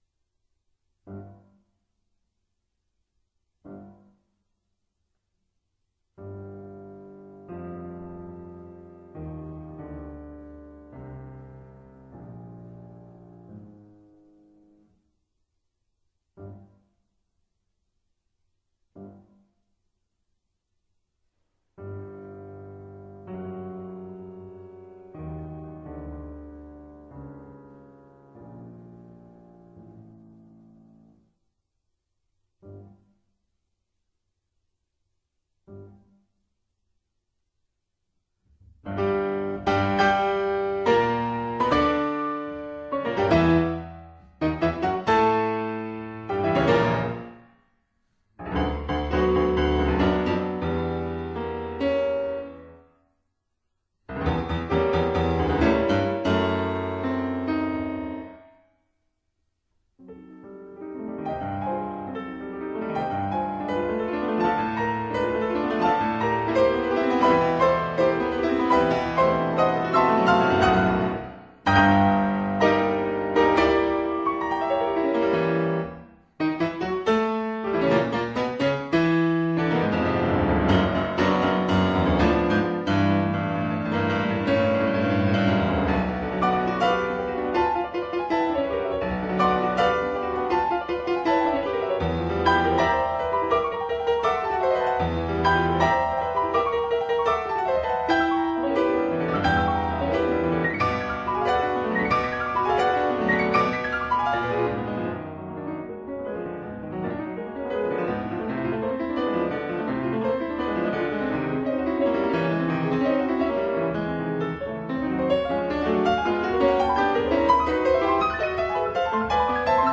Instrument: Piano
Style: Classical
piano-sonata-in-b-minor-s-178.mp3